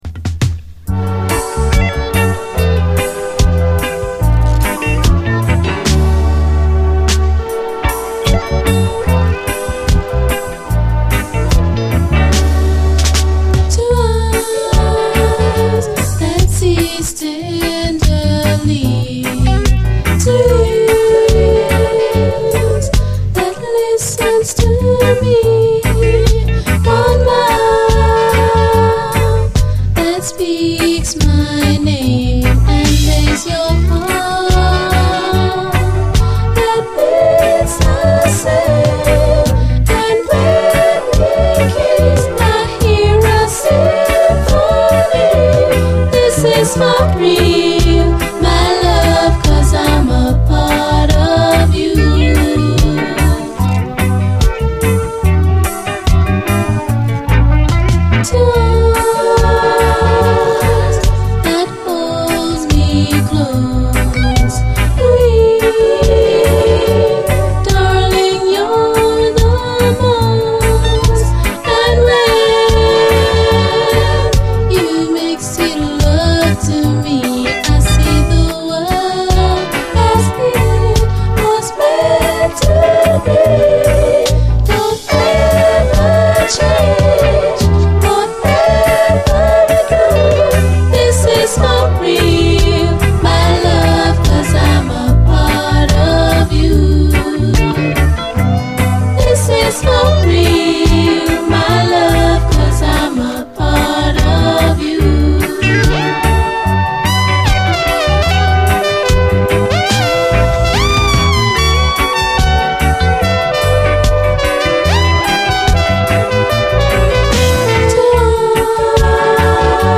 REGGAE
激ドリーミー！感動のキラーUKラヴァーズ！